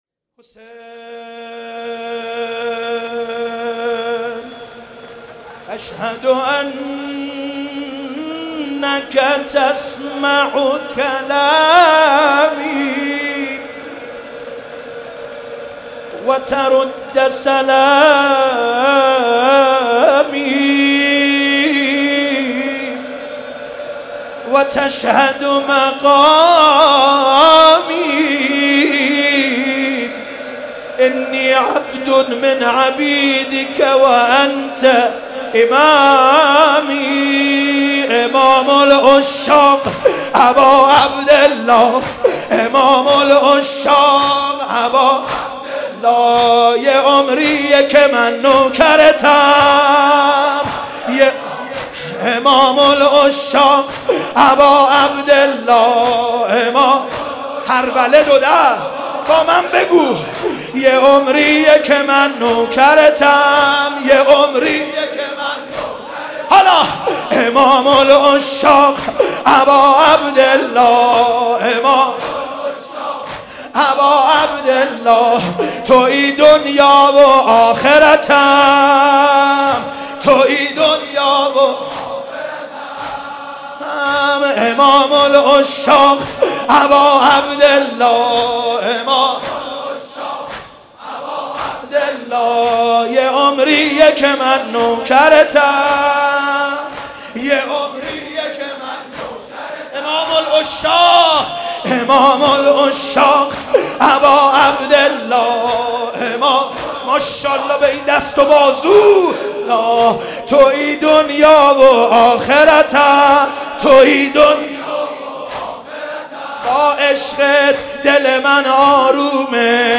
صوت مراسم شب پنجم محرم ۱۴۳۷ هیئت رزمندگان اسلام قم ذیلاً می‌آید:
أشْهَدُ اَنَّكَ تَسْمَعُ كَلامى وَترُدُّ سَلامى - شور ابتهال